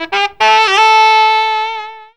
COOL SAX 7.wav